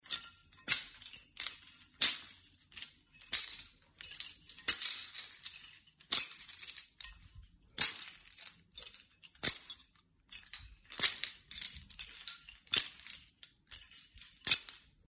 Подбрасывание ключей